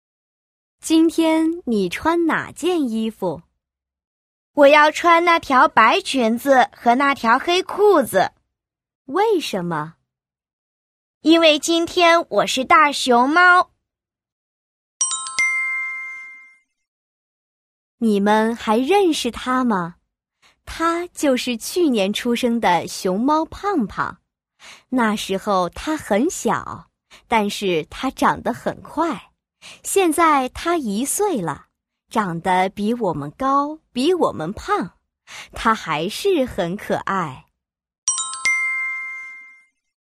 Đoạn hội thoại 1:
Đoạn hội thoại 2: